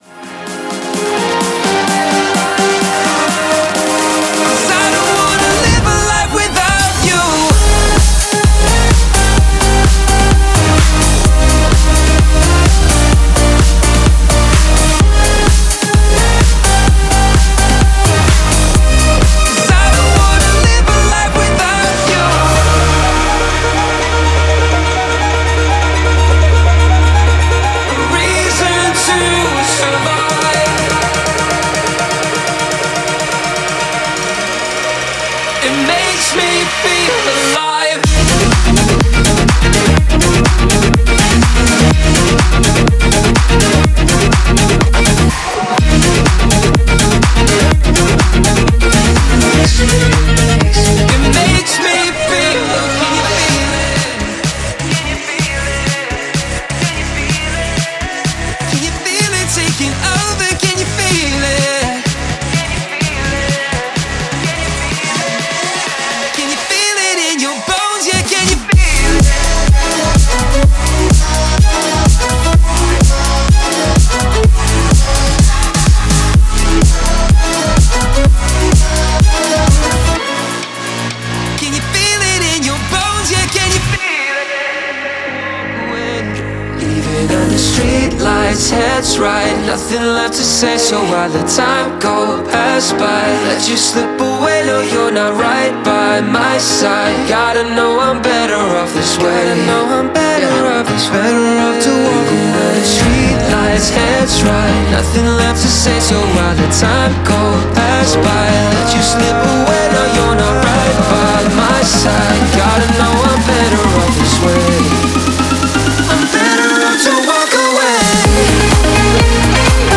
Genre:Progressive House
豊かなボーカル要素として、132の男性ボーカルループとワンショットを収録。